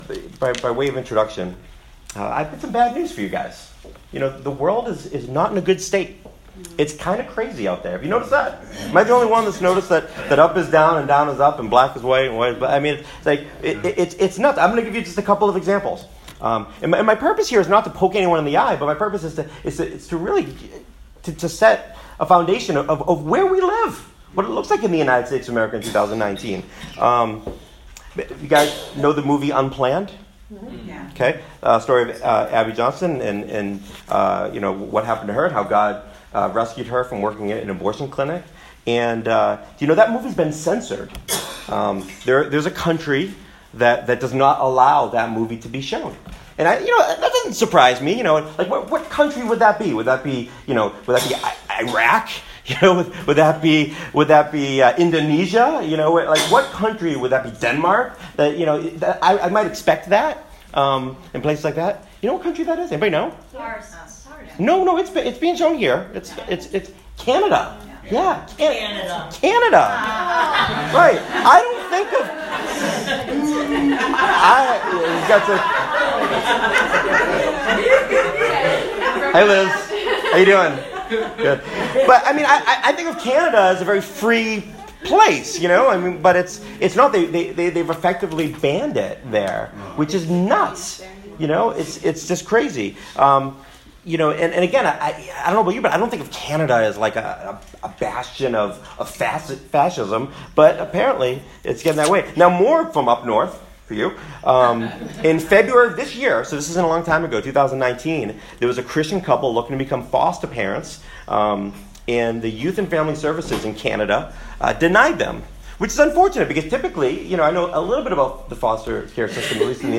New Sermon – Wolves, a Serpent, and a Dove – The Great News!
My friends at “Church Alive” in Portsmouth, NH, invited me to bring the Word to them, and I’m so glad they did.